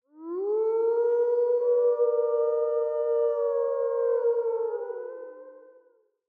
Babushka / audio / sfx / Animals / SFX_Wolfs_Howl_01.wav
SFX_Wolfs_Howl_01.wav